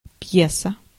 Ääntäminen
IPA: [stʏk]